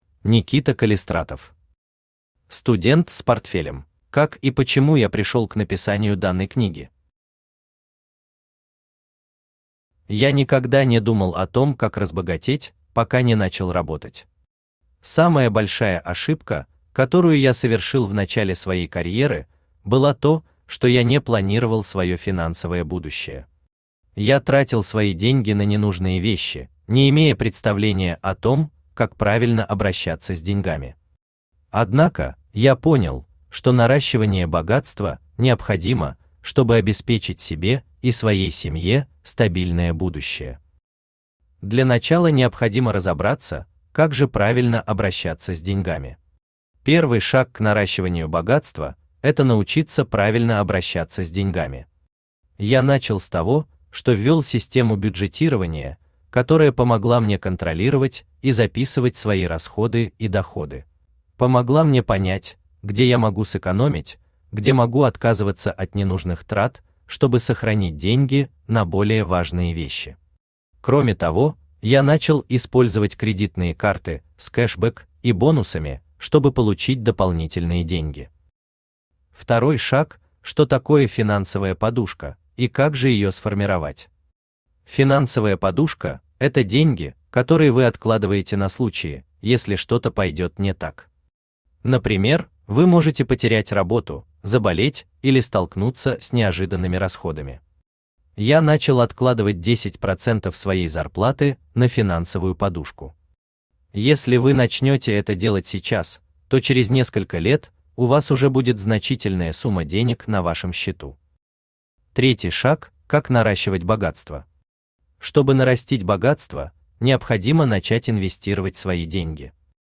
Аудиокнига Студент с портфелем | Библиотека аудиокниг
Aудиокнига Студент с портфелем Автор Никита Вячеславович Калистратов Читает аудиокнигу Авточтец ЛитРес.